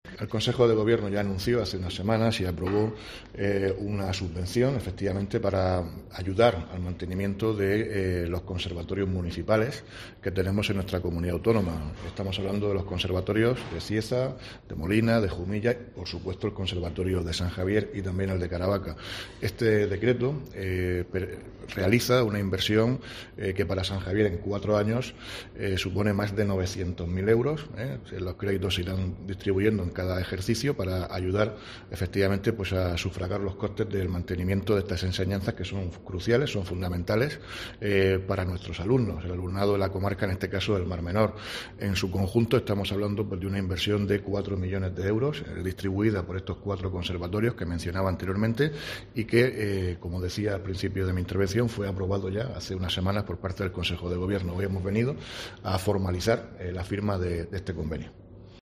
Víctor Marín, consejero de Educación